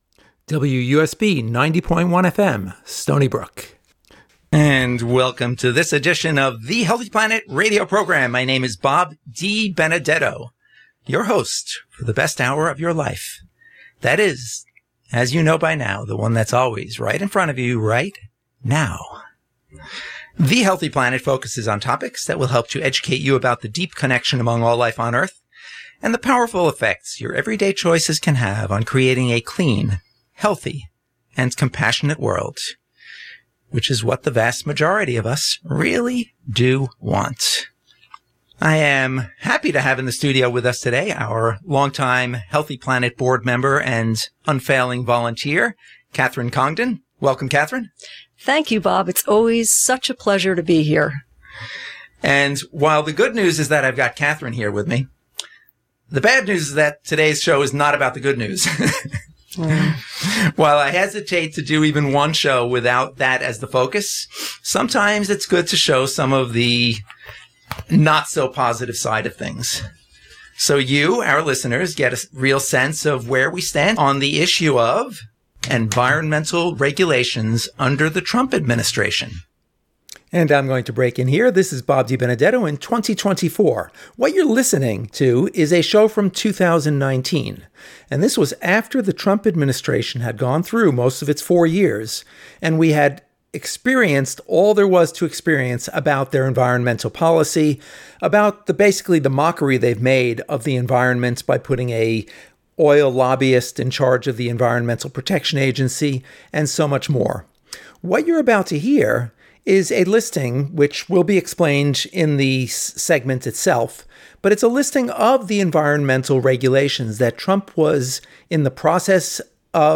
Since past actions are the best predictors of future behavior, and with the crucial elections in the US just five months away, we felt it valuable to rebroadcast this show, which originally aired at the end of Donald Trump’s presidency in 2019.